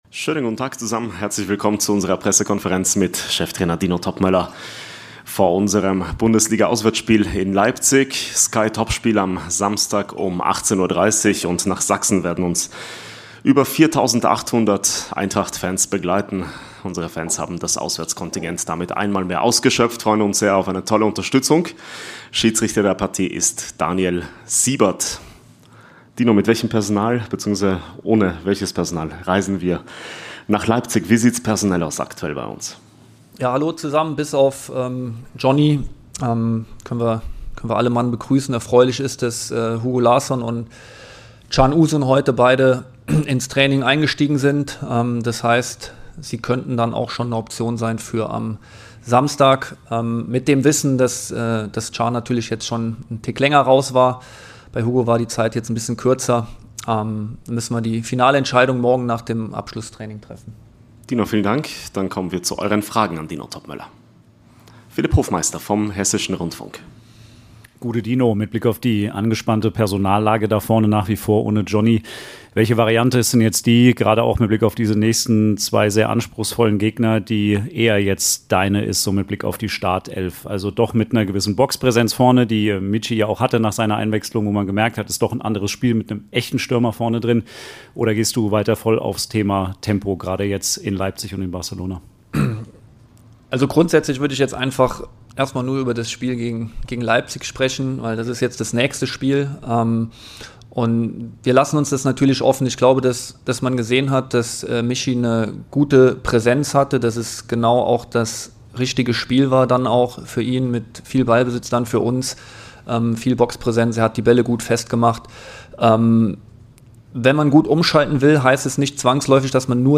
Cheftrainer Dino Toppmöller stellt sich den Fragen der Journalisten auf der Pressekonferenz vor Leipzig.